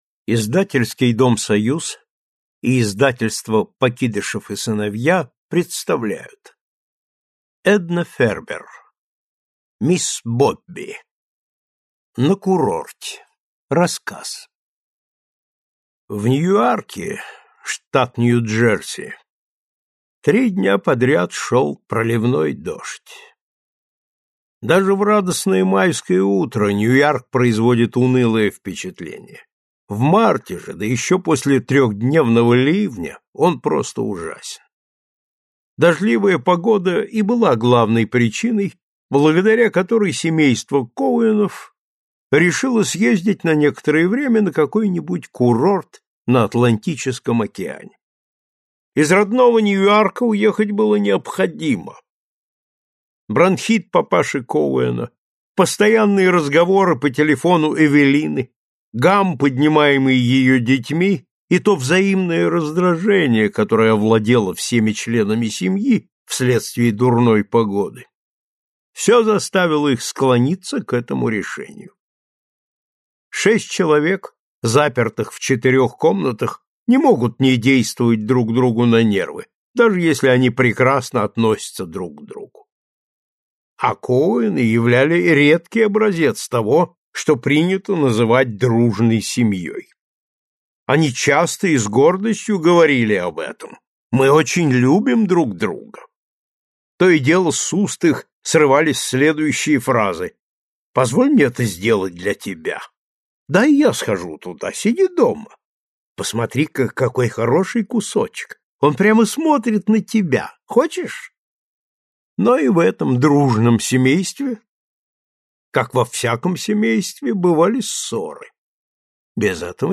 Аудиокнига Мисс Бобби и другие рассказы | Библиотека аудиокниг